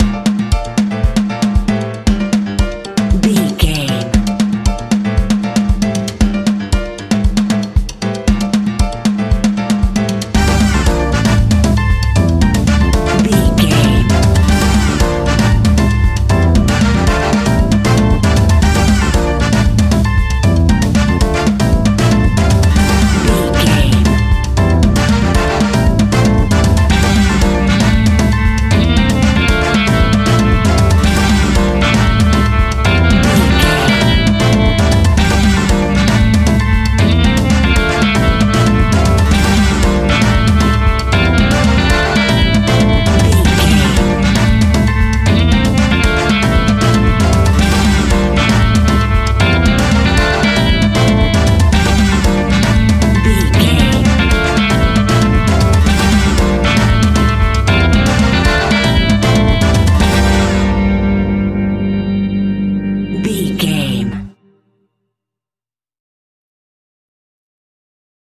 Aeolian/Minor
latin
salsa
uptempo
percussion
brass
saxophone
trumpet